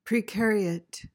PRONUNCIATION:
(pri-KAIR-ee-uht)